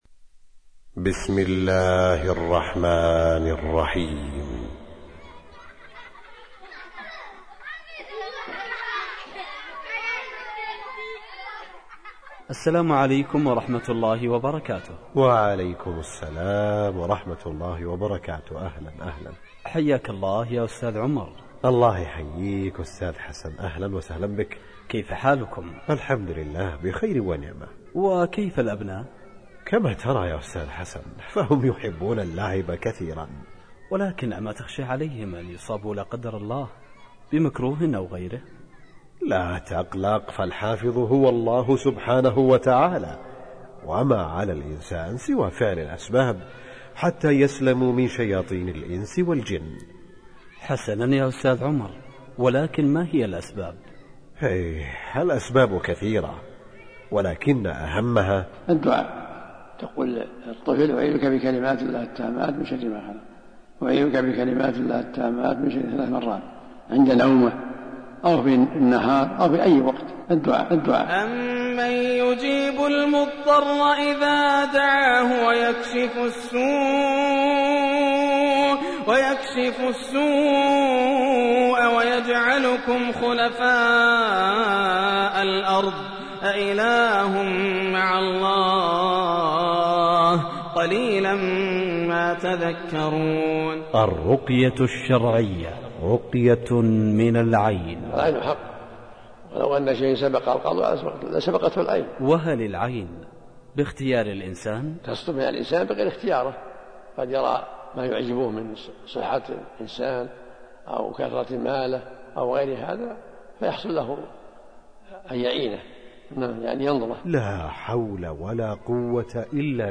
الرقية الشرعية - المكتبة الإسلامية